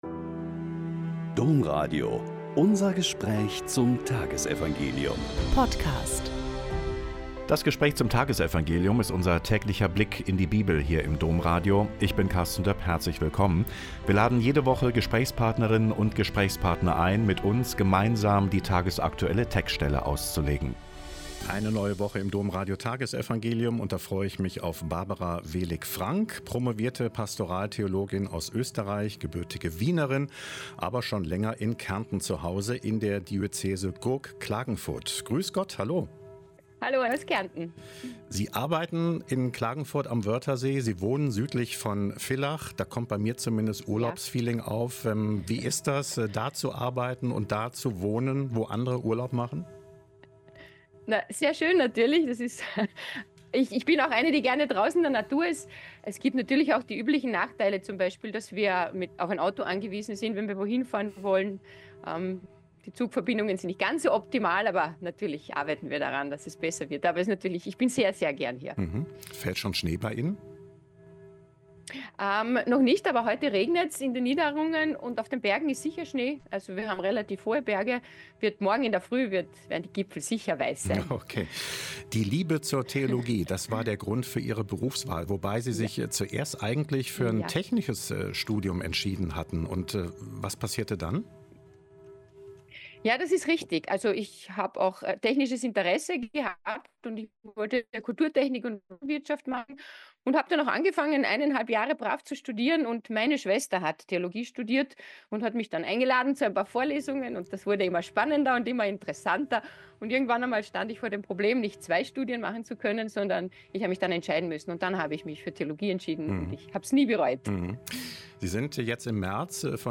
Lk 18,35-43 - Gespräch